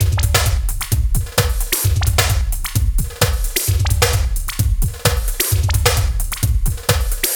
TEK NO LOOP 2.wav